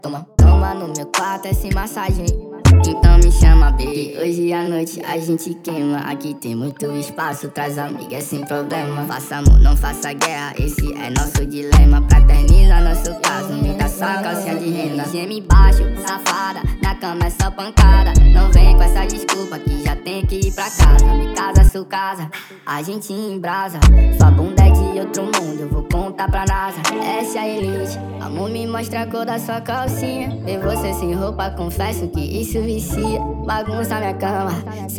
Жанр: Рэп и хип-хоп
# Hip-Hop